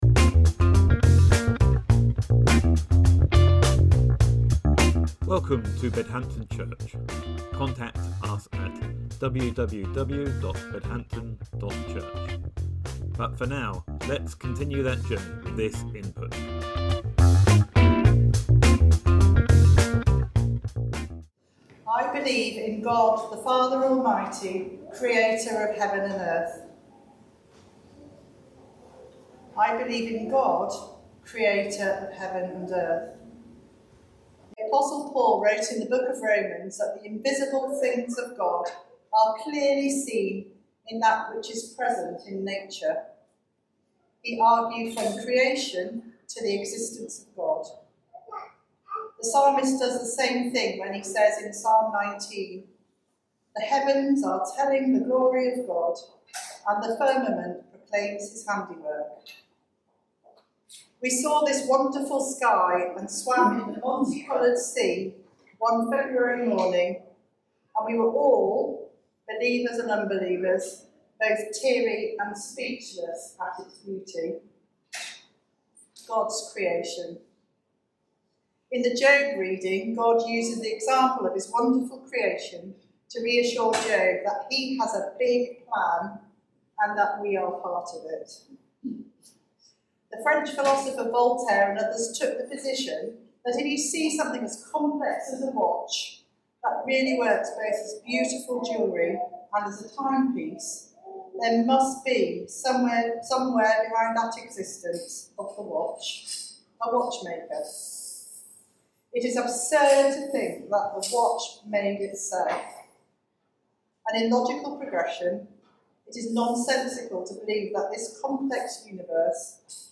Sermon September 15th, 2024 – Creeds: I believe in God, the Father…